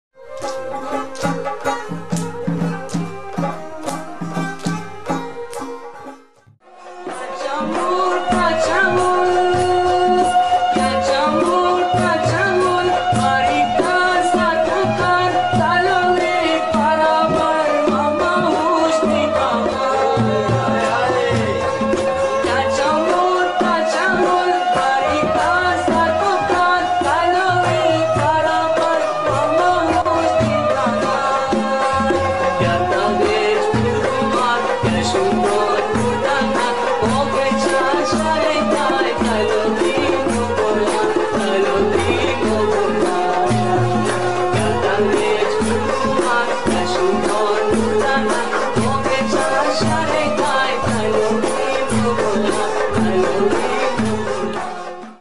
khowar old song